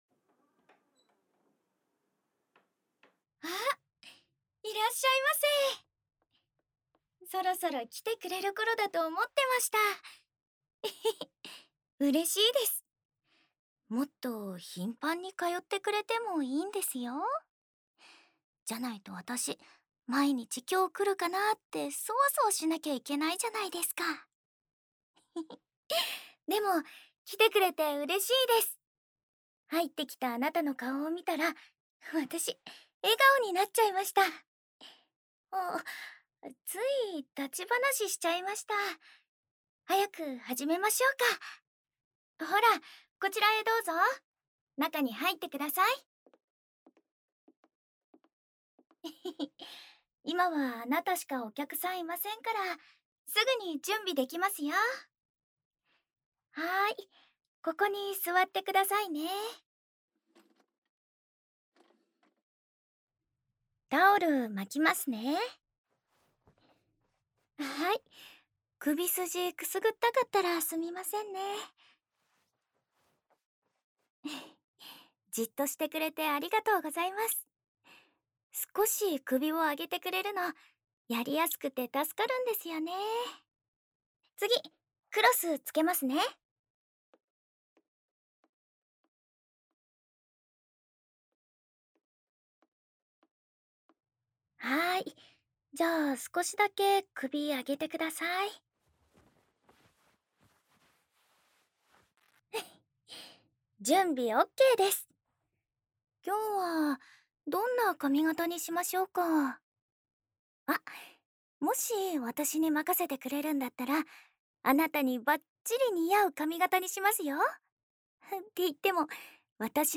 日常/生活 温馨 治愈 姐姐 环绕音 ASMR 低语
el62_01_『抵達美容院，前來迎接的詩織在近距離下面露笑容』.mp3